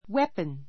weapon wépən ウェ ポン 名詞 武器 nuclear weapons nuclear weapons 核 かく 兵器 Her smile is her best weapon.